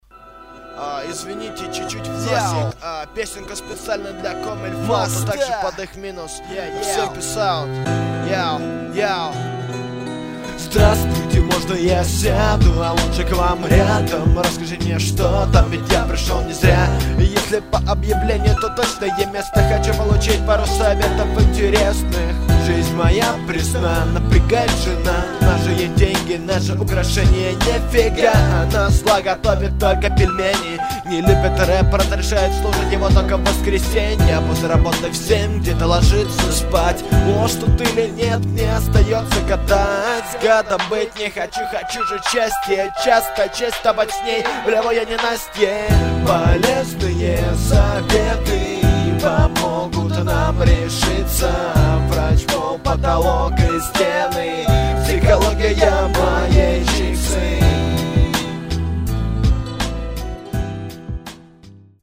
Демо-музыка
как то тяжко ты читанул, а текст ничёшный..
в нос ж=)))а более хотел пропеть...=)))